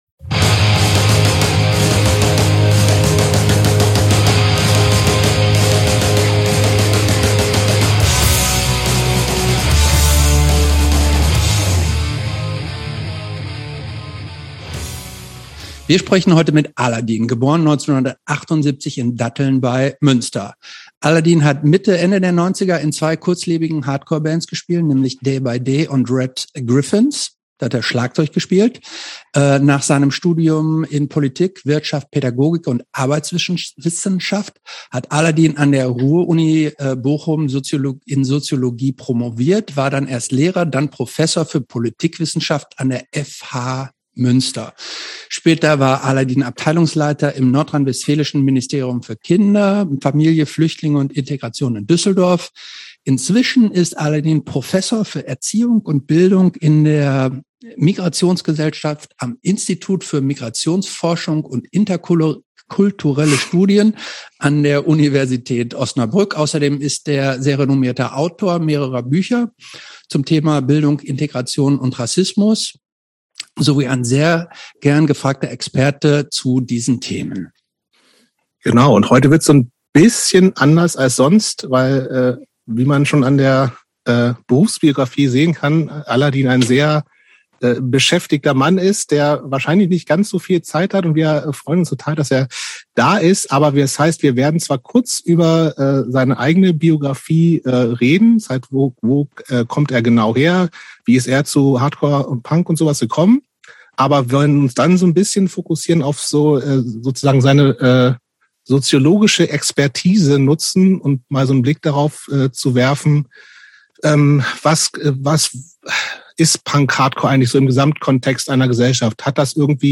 Im Gespräch mit Aladin.